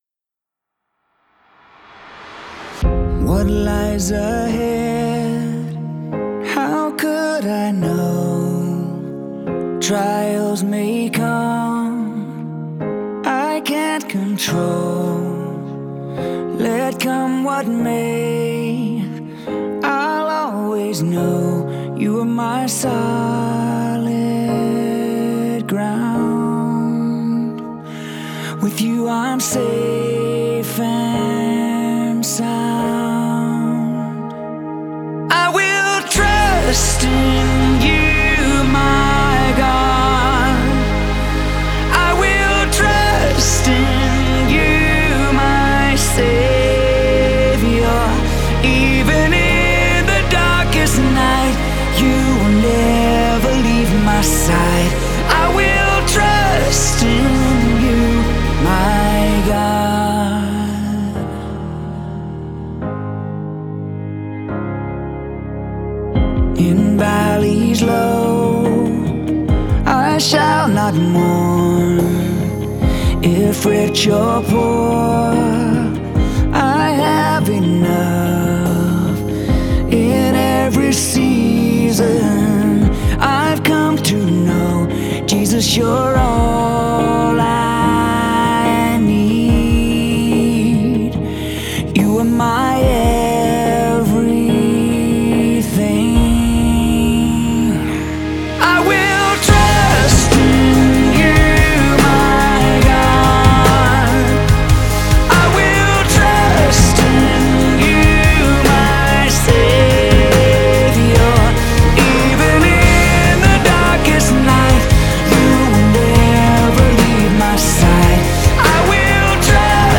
pop soulful style